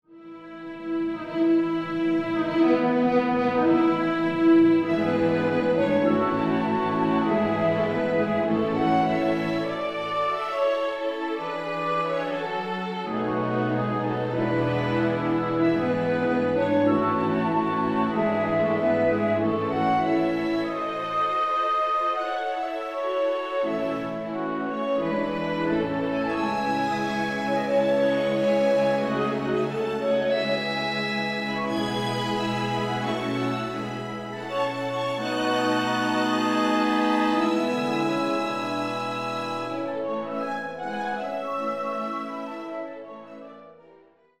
Orchester-Sound